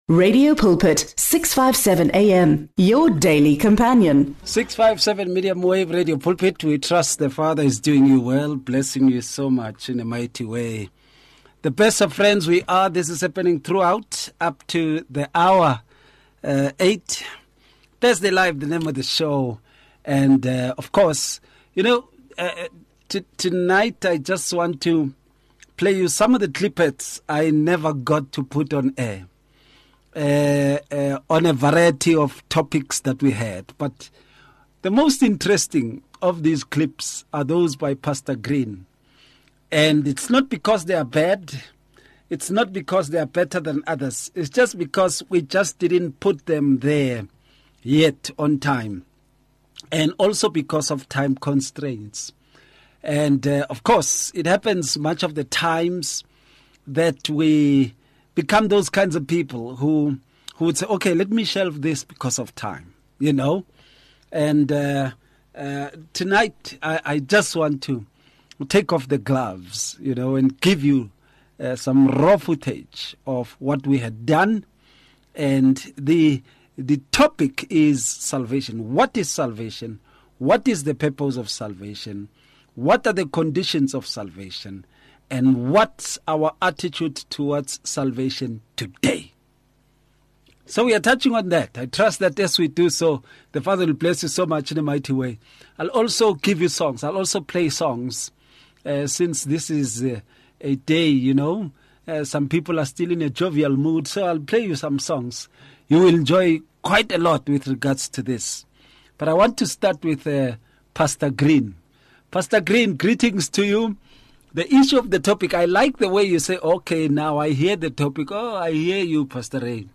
conversation